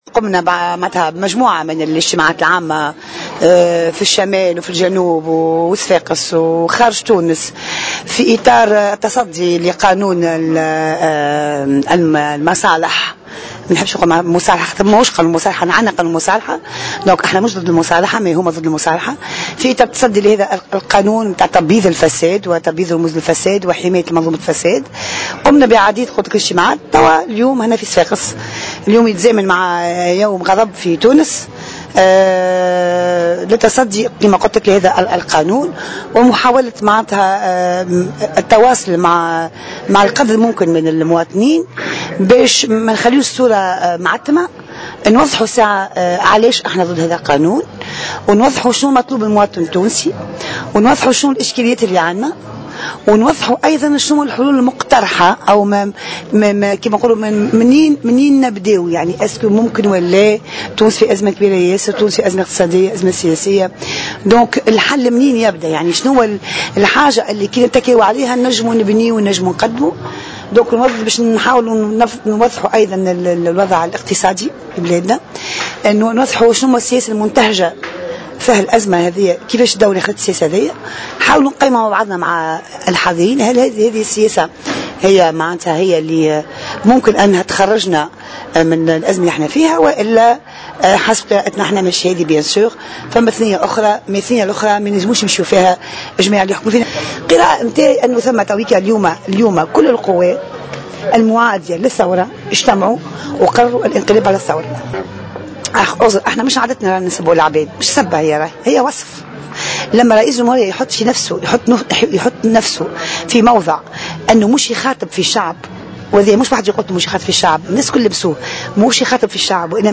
وأضافت في تصريح لمراسل الجوهرة اف ام خلال إجتماع عام عقده حزبها مساء اليوم السبت في صفاقس، أن رئيس الجمهورية الباجي قائد السبسي توجه بخطابه الأخير إلى مجموعة ضيقة انتهازية ومافيا ولم يخاطب الشعب، كما استعمل كل صلاحياته لحماية هذه الفئة، ووضع نفسه في محل رئيس "مافيا"، وفق تعبيرها.